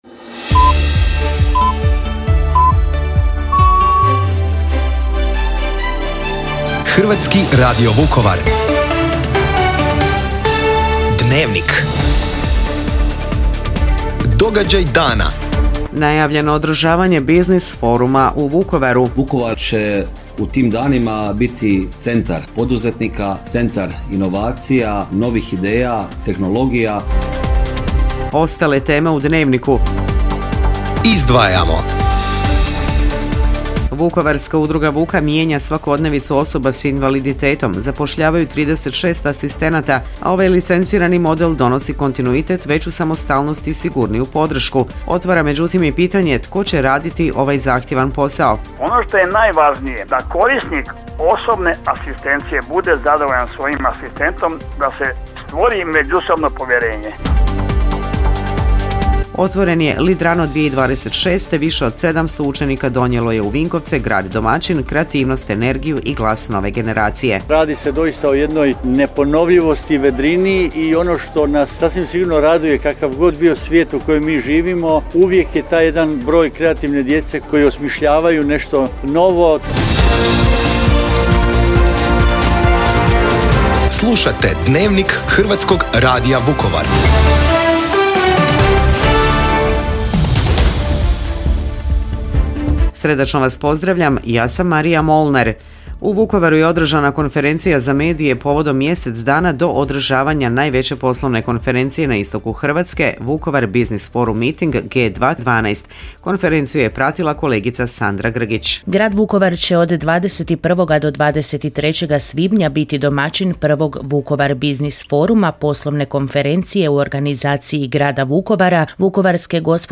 Vukovar u fokusu: Business, inkluzija i glas mladih u današnjem izdanju naše središnje informativne emisije Dnevnik HRV-a